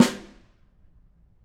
Snare2-HitSN_v5_rr2_Sum.wav